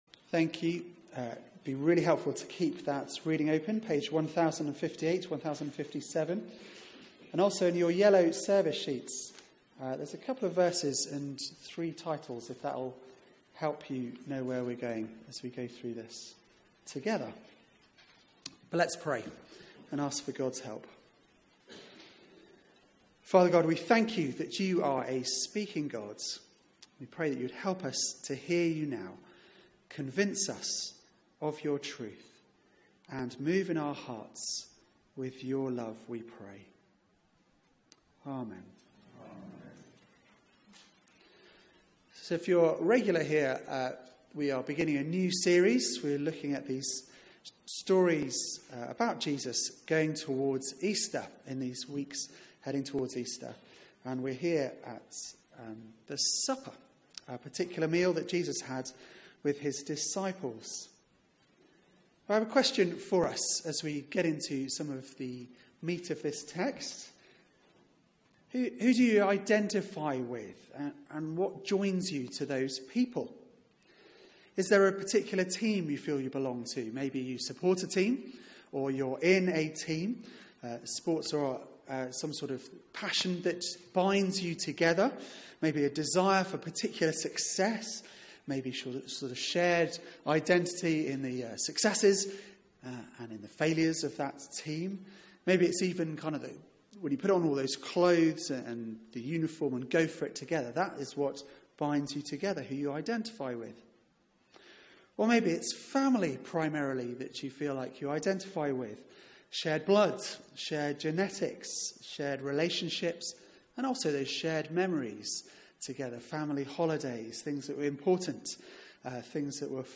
Media for 9:15am Service on Sun 05th Mar 2017
Theme: The Supper Sermon